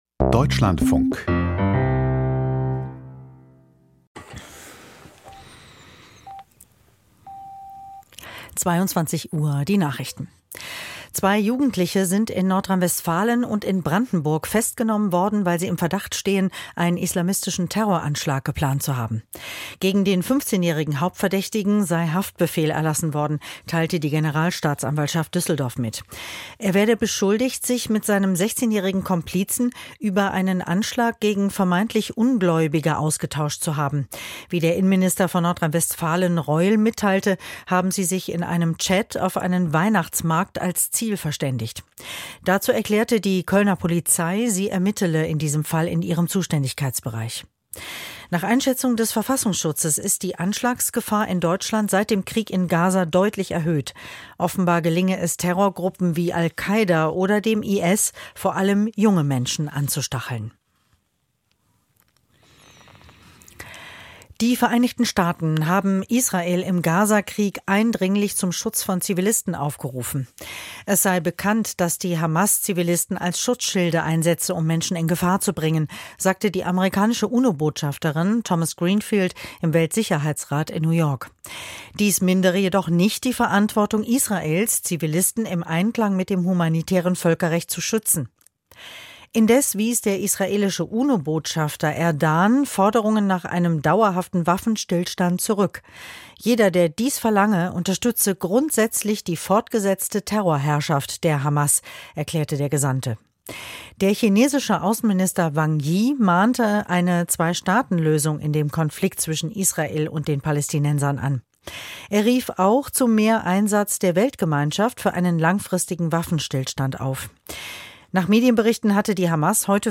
Die Nachrichten 9,590 subscribers updated 2h ago Theo dõi Đăng ký theo dõi Nghe Đang phát Chia sẻ Đánh dấu tất cả (chưa) nghe ...